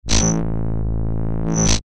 Звуки лазерного оружия
Звук лазерного луча